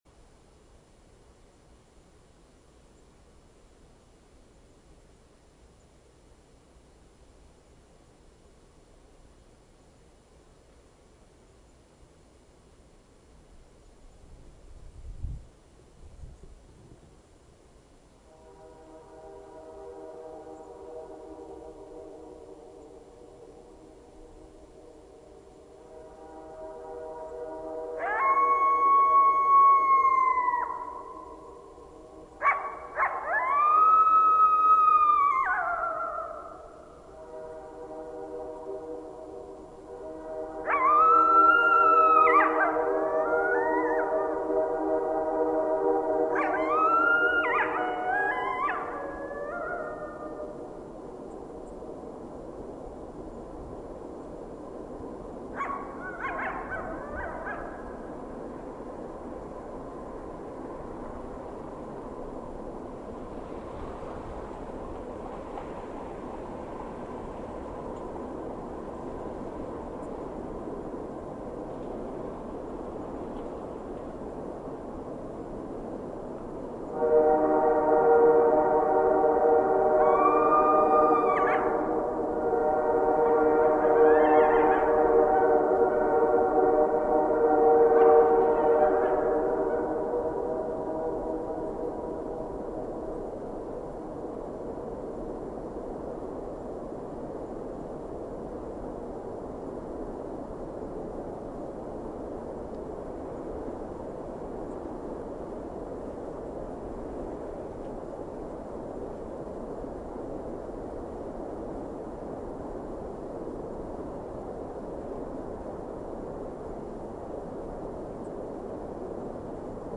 Coyotes 2 Bouton sonore